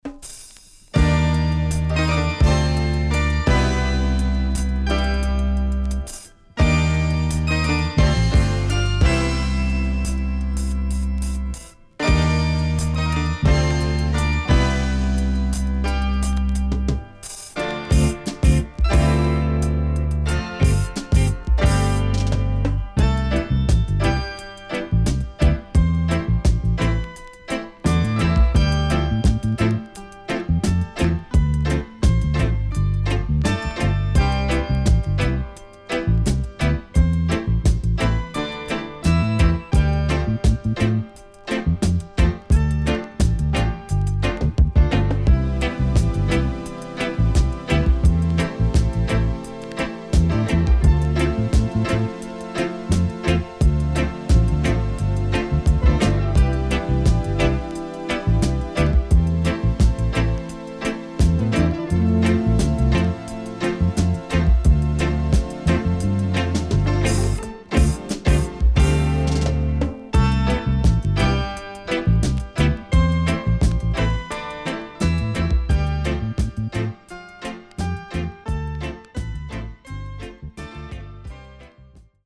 【12inch】
SIDE A:少しチリノイズ入りますが良好です。